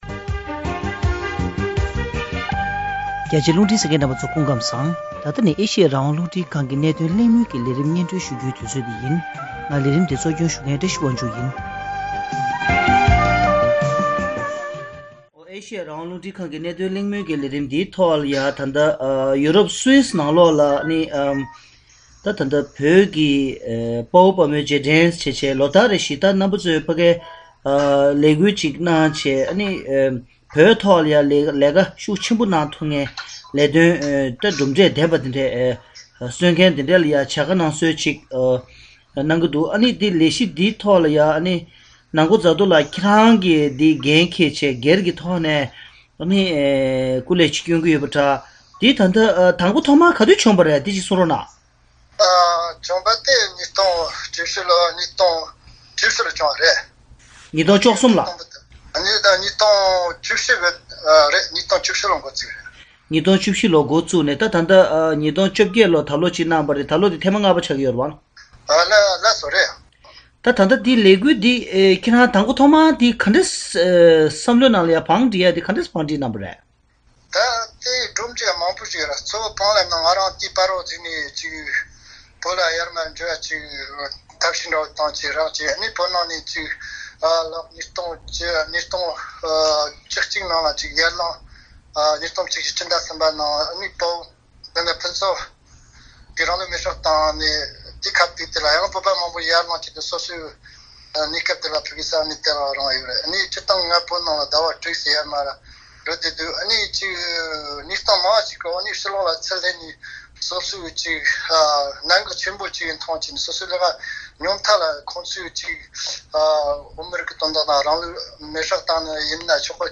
༄༅། །གནད་དོན་གླེང་མོལ་གྱི་ལས་རིམ་ནང་།